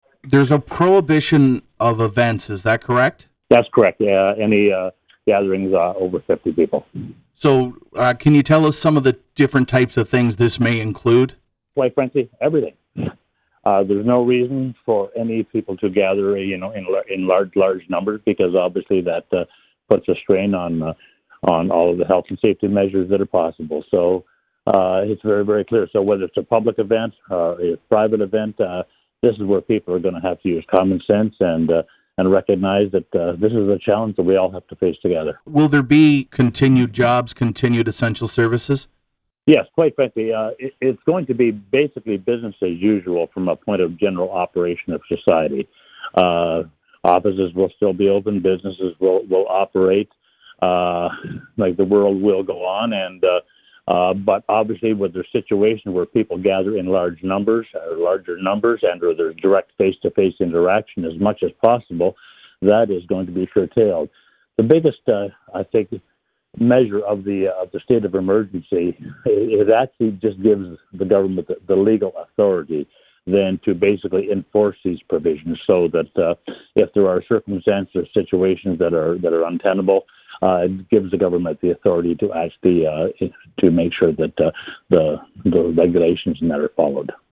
Hastings Lennox & Addington MPP Daryl Kramp spoke with Quinte News Tuesday morning.